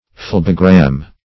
Phlebogram \Phleb"o*gram\, n. [Gr. fle`ps, flebo`s + -gram.]